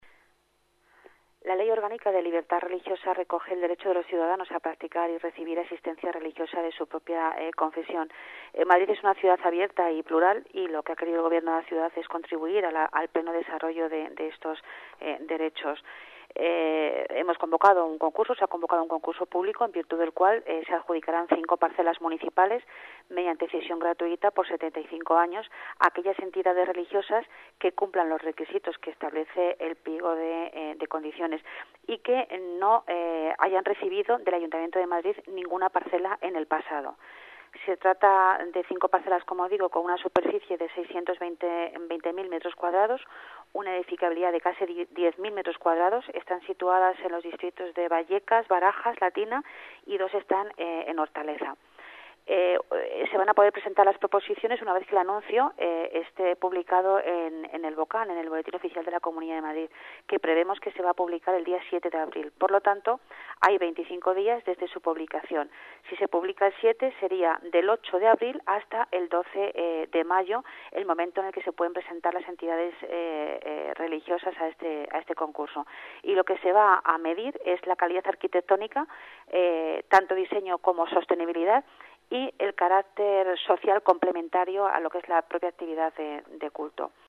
Nueva ventana:Declaraciones de la delegada de Urbanismo, Pilar Martínez: cesión suelo municipal para entidades religiosas